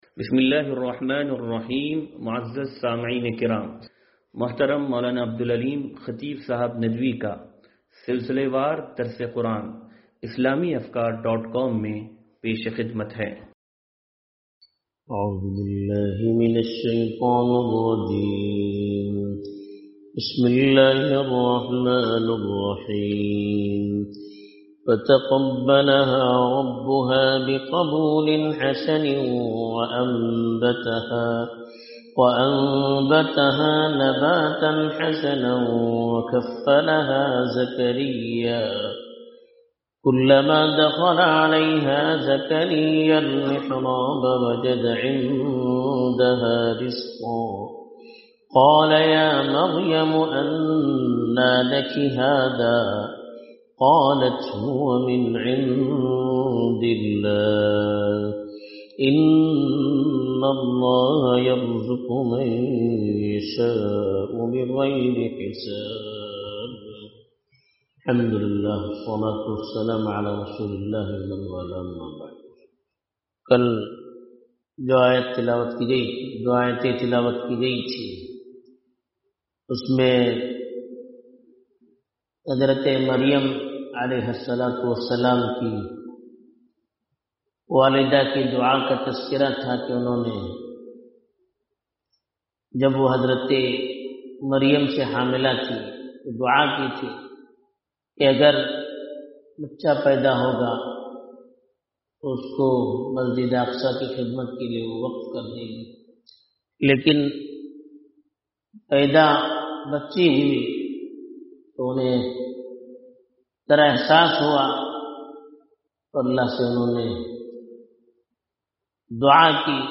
درس قرآن نمبر 0238